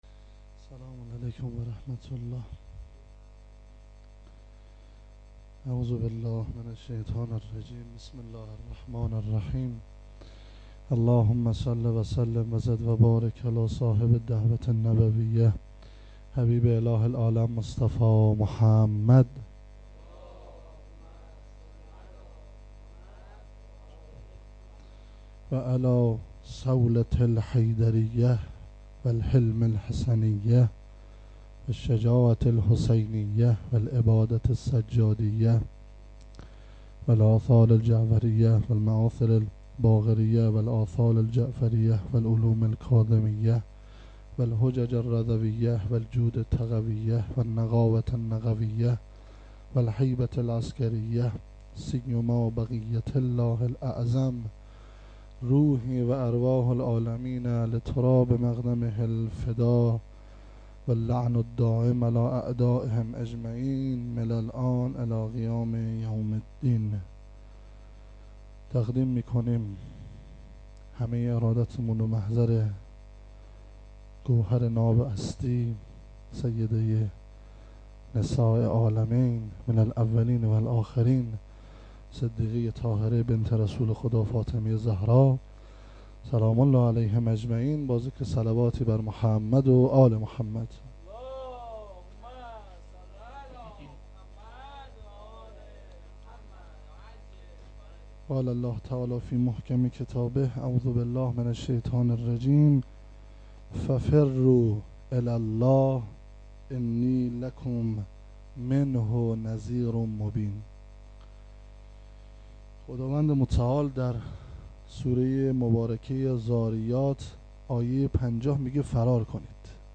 sokhanrani